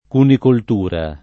cunicoltura [ kunikolt 2 ra ]